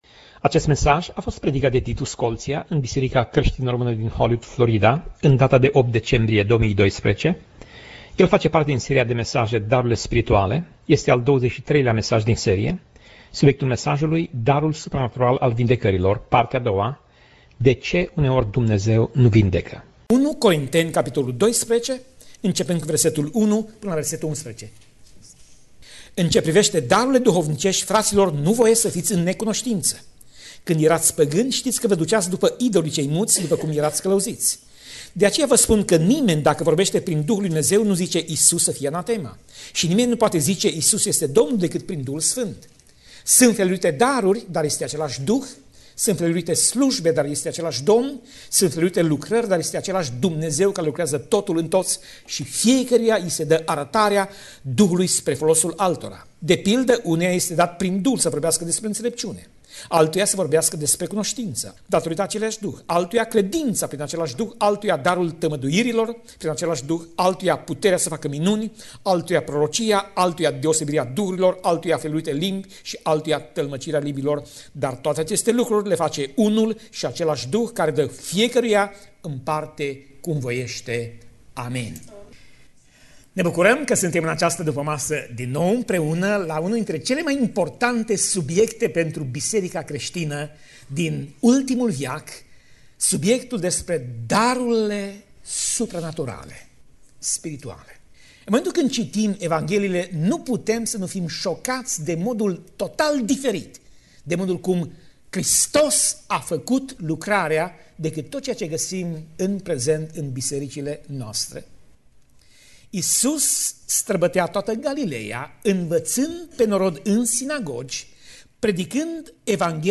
Pasaj Biblie: 1 Corinteni 12:1 - 1 Corinteni 12:11 Tip Mesaj: Predica